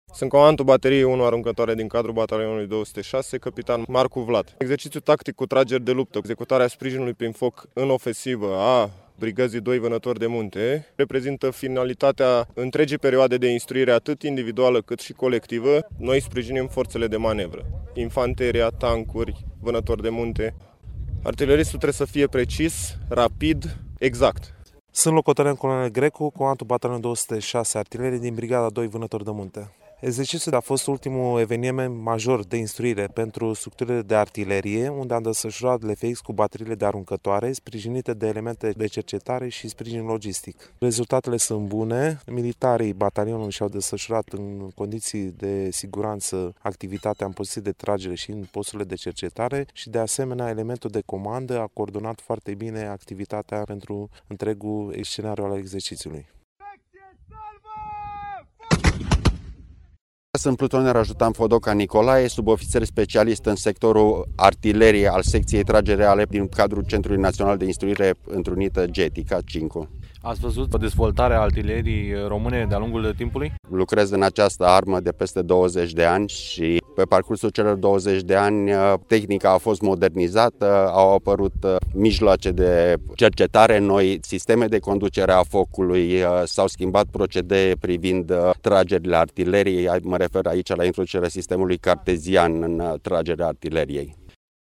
reportaj-Cincu-178-de-artilerie-OK.mp3